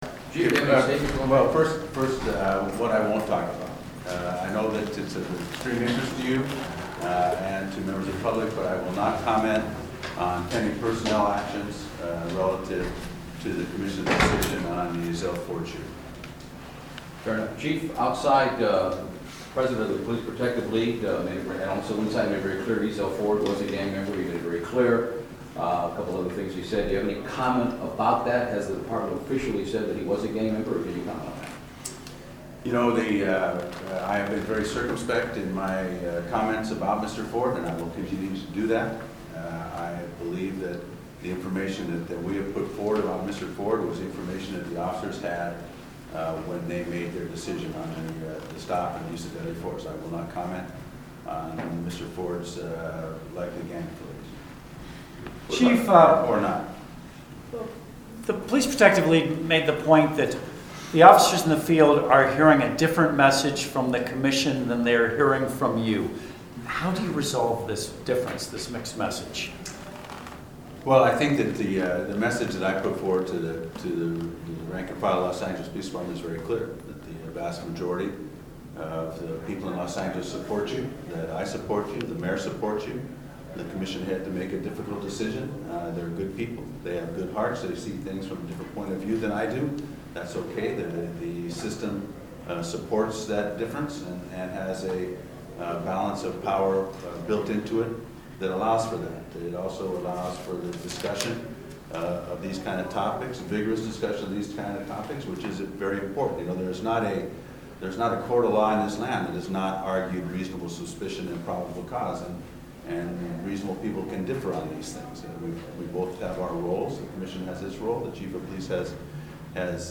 June 16, 2025 - Chief Beck Takes Media Questions after Police Commission Meeting
cop-media-update-after-comm-mtg-6-16-15.mp3